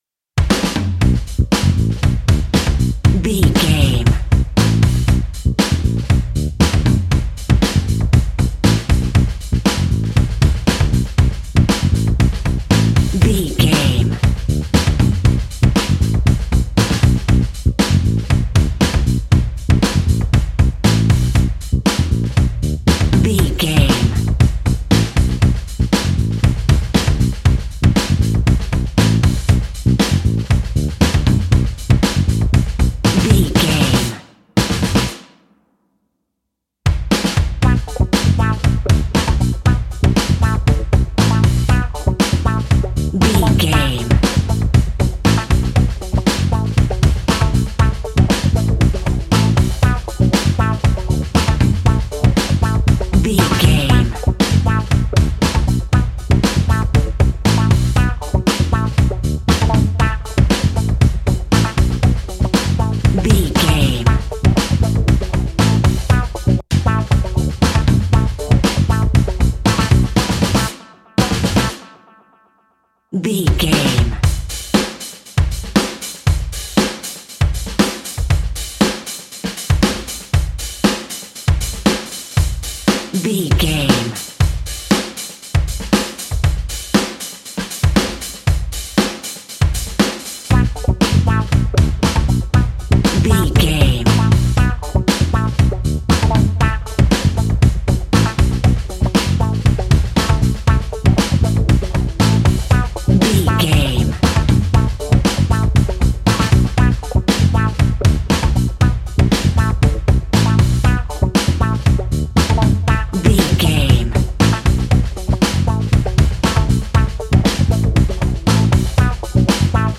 Aeolian/Minor
G♭
groovy
lively
electric guitar
electric organ
bass guitar
saxophone
percussion